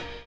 LO FI 7 OH.wav